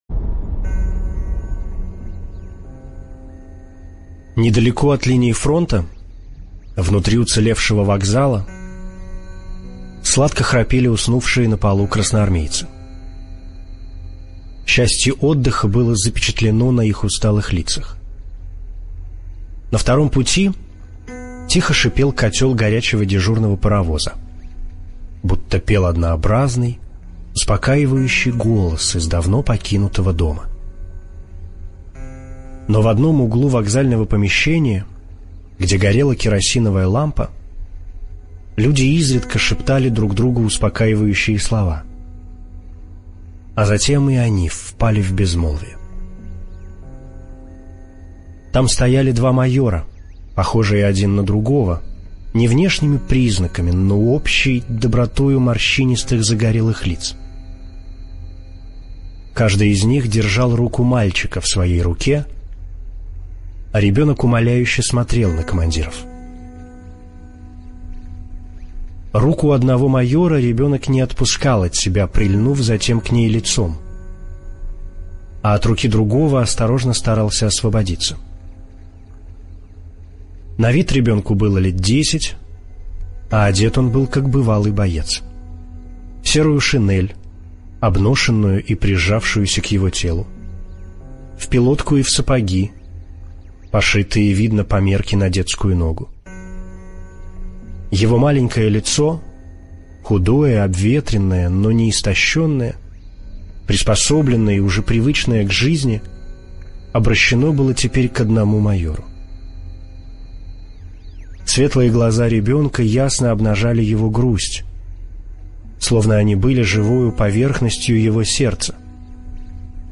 Маленький солдат - аудио рассказ Платонова - слушать онлайн